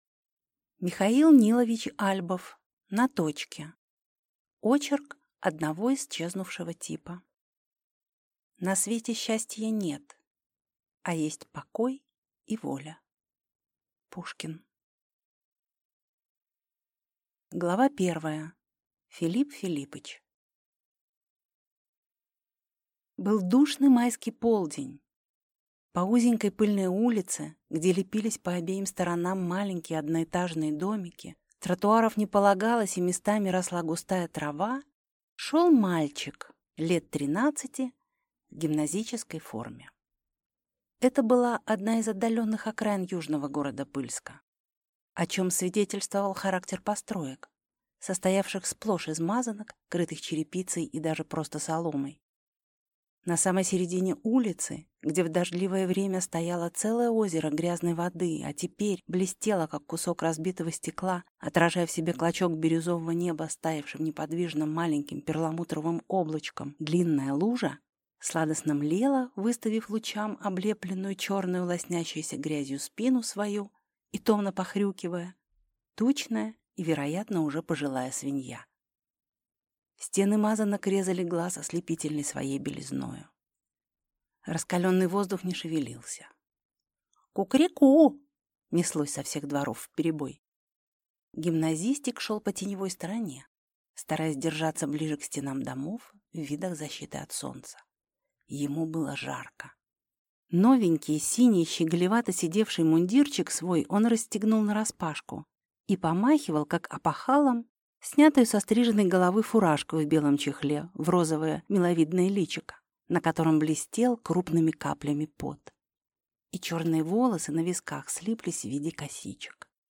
Аудиокнига На точке | Библиотека аудиокниг